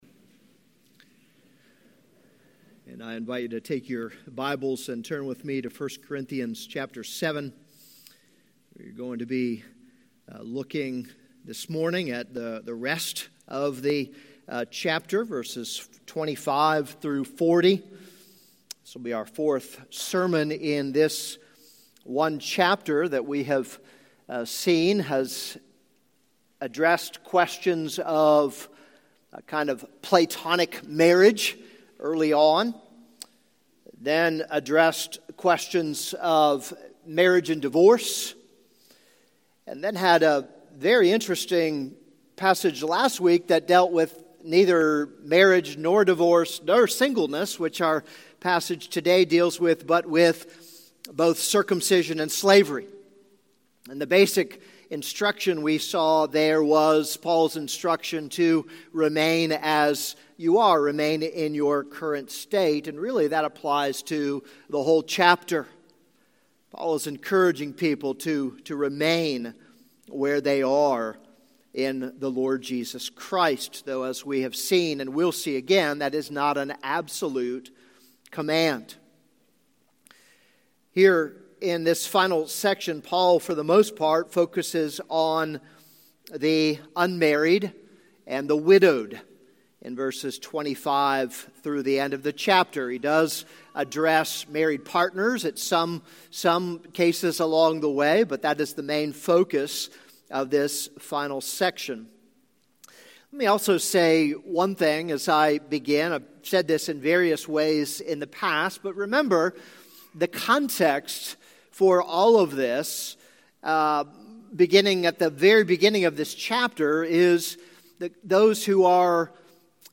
This is a sermon on 1 Corinthians 7:25-40.